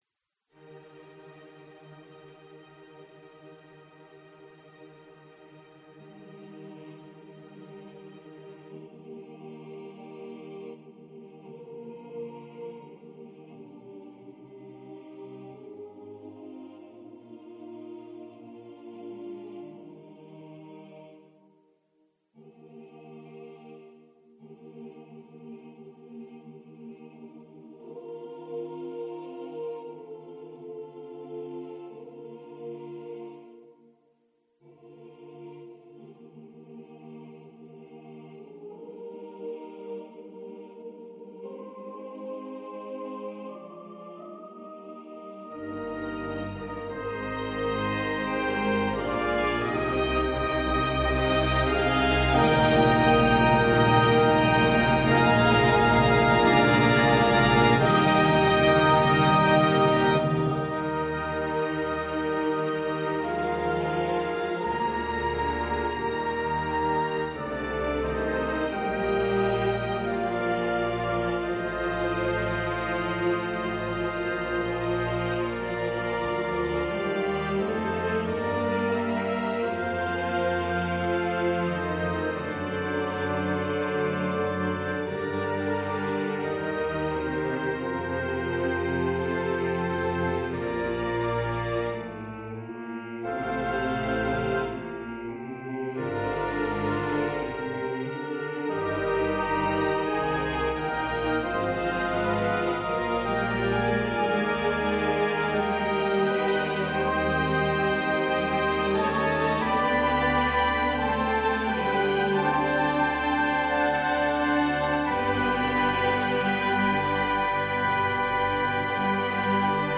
anthem for SATB choir and organ
Audio rendering of the orchestral version.